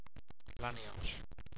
LAN-KNEE-OSH (last syllable rhymes with posh)